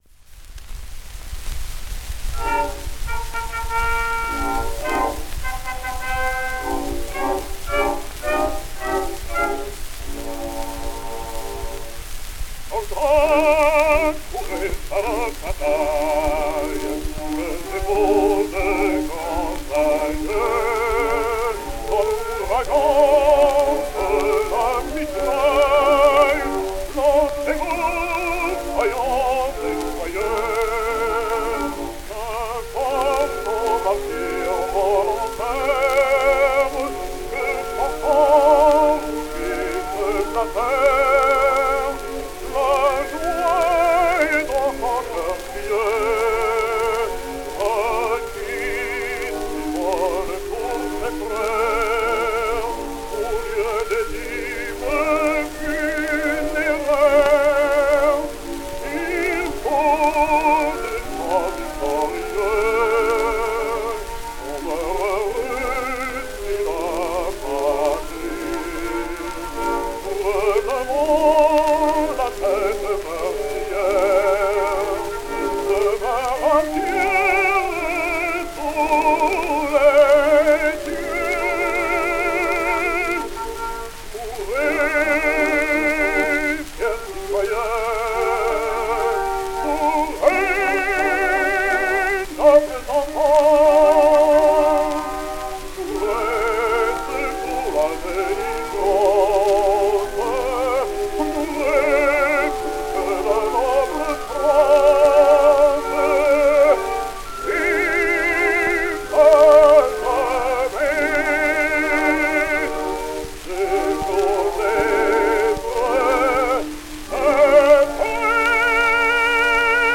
Emerson, New York City, 1917